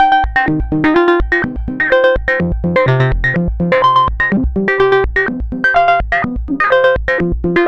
tx_synth_125_anaarps_CMin.wav